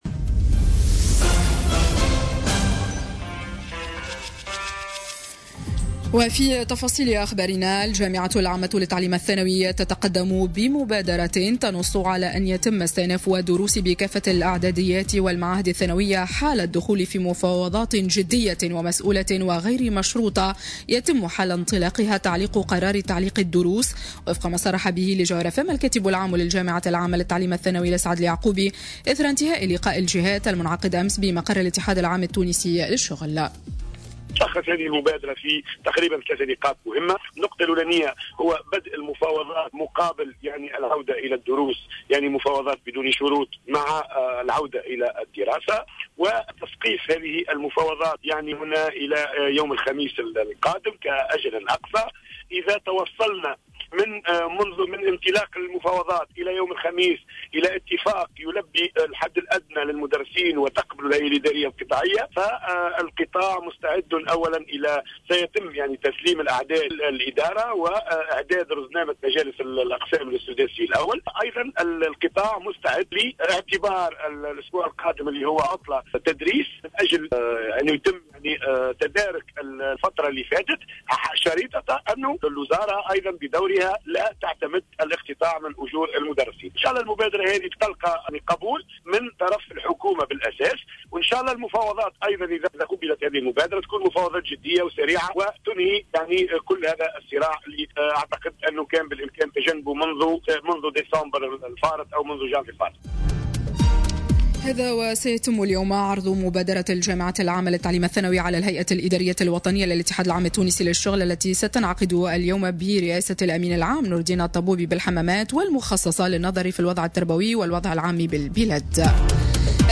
نشرة أخبار السابعة صباحا ليوم الإثنين 23 أفريل 2018